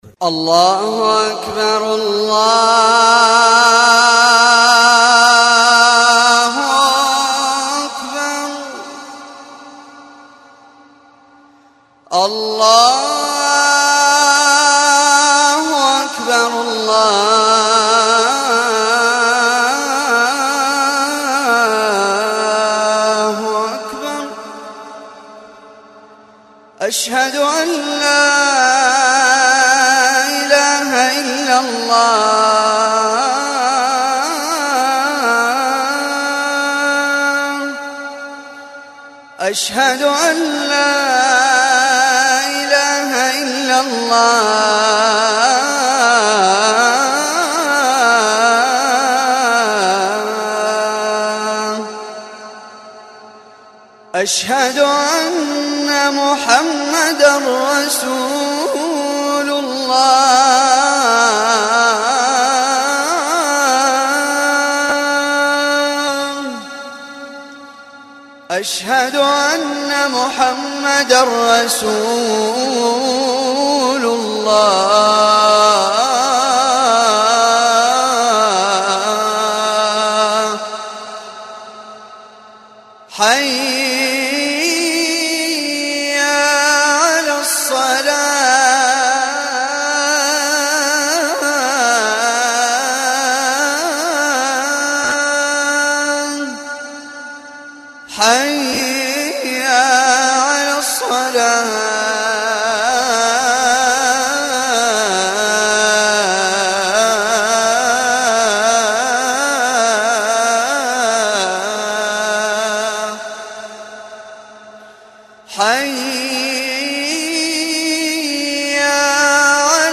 آذان
athan15.mp3